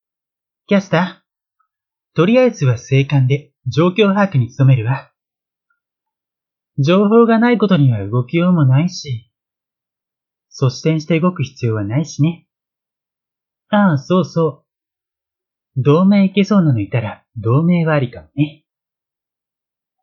占い師を生業とする男性（オネエ口調）
SampleVoice02